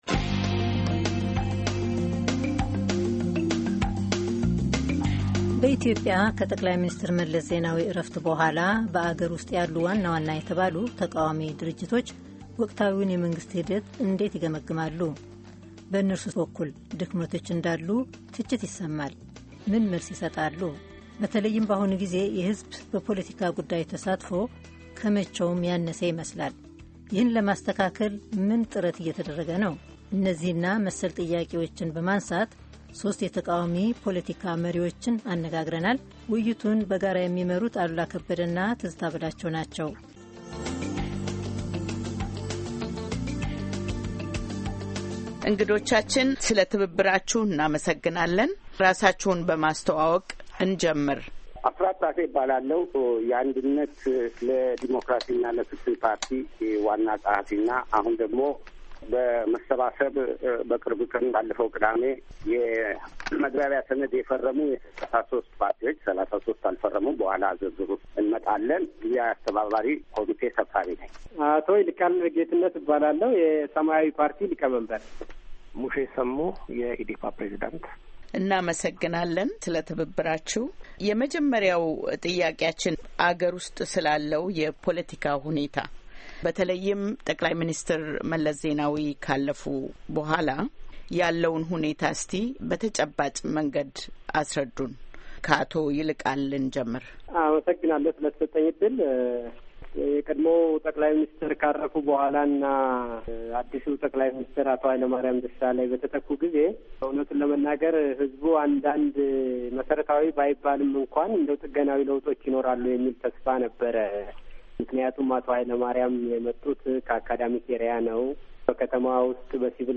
ውይይት በኢትዮጵያ የተቃዋሚ ፓርቲዎች ሚናና ወቅታዊ ፖለቲካ፤ ክፍል አንድ